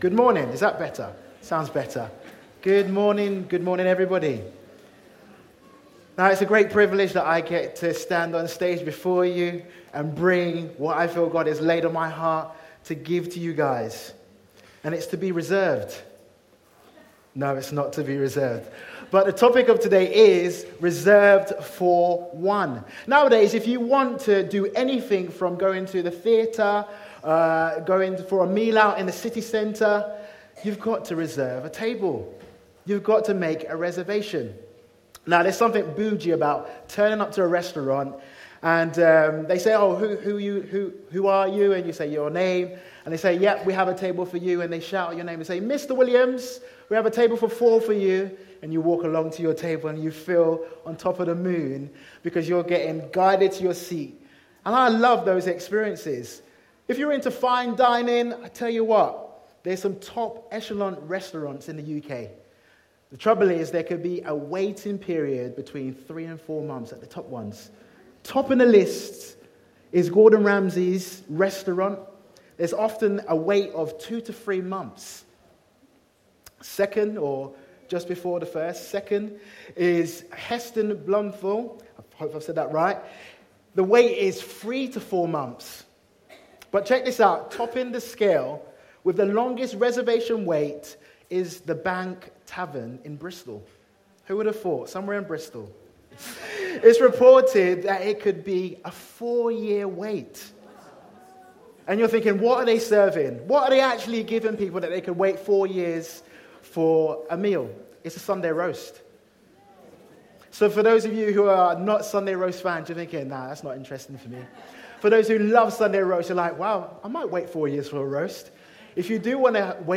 For One Preacher